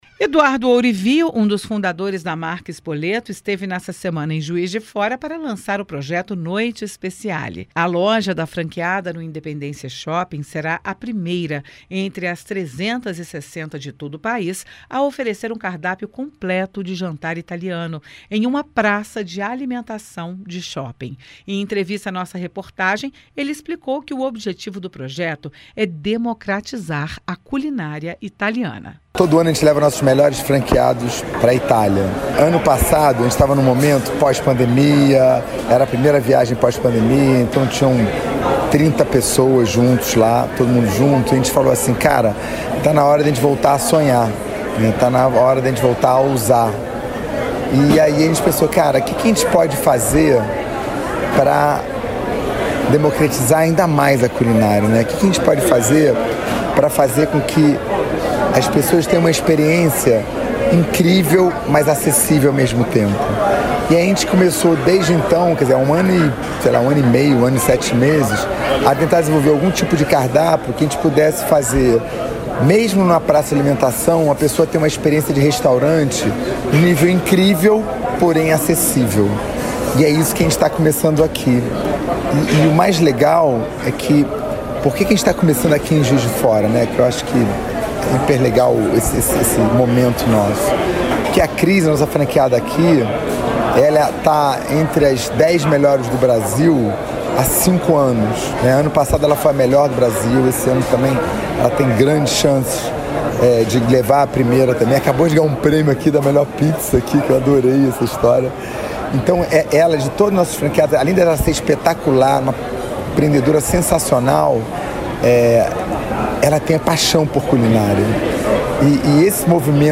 Em entrevista à Itatiaia, ele explicou que o objetivo é democratizar o acesso à culinária italiana.